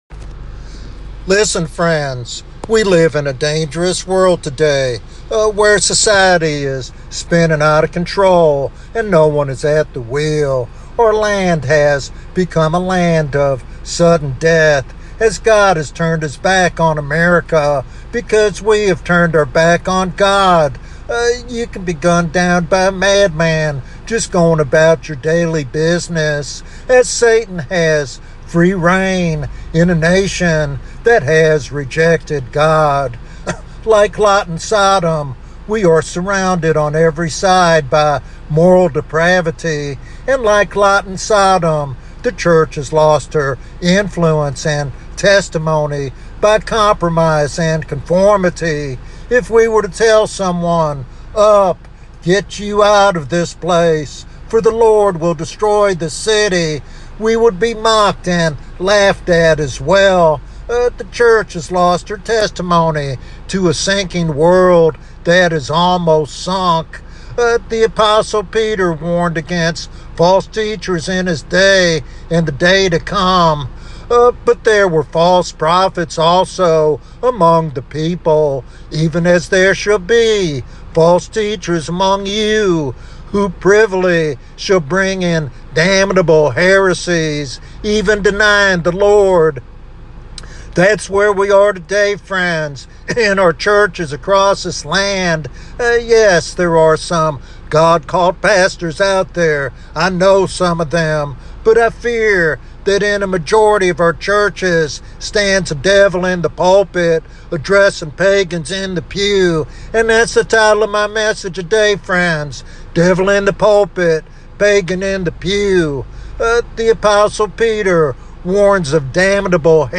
This message challenges the complacency of modern Christianity and urges a return to biblical truth.